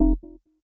bonk.ogg